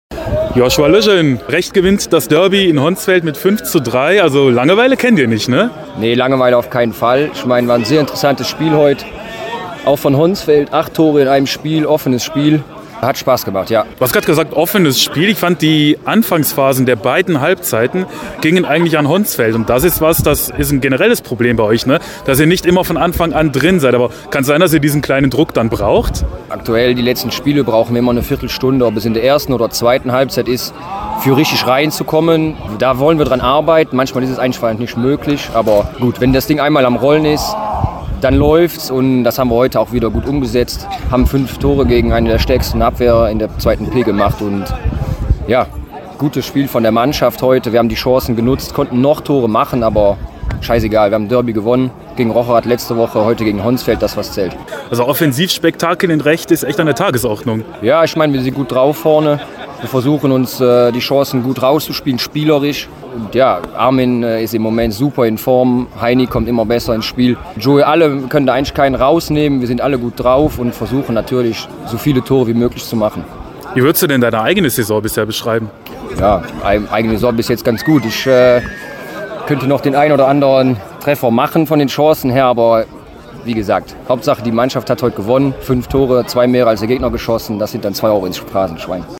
Mittelfeldspieler